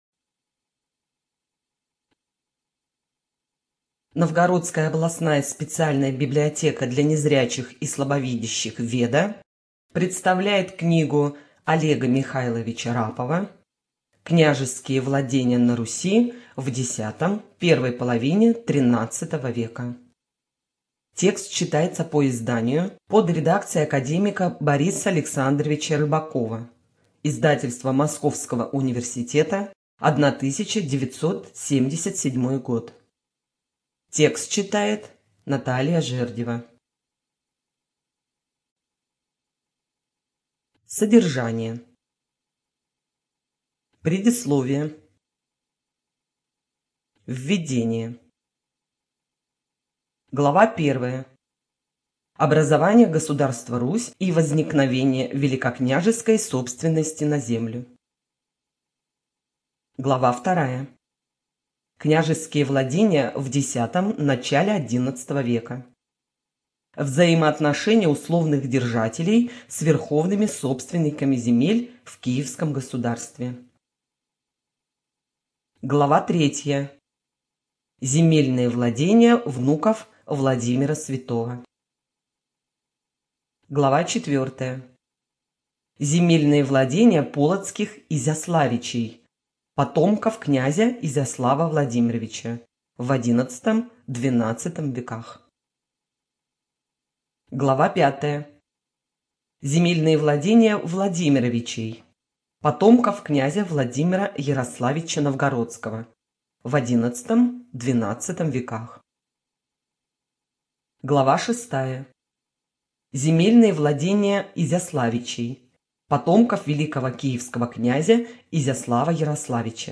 Студия звукозаписиНовгородская областная библиотека для незрячих и слабовидящих "Веда"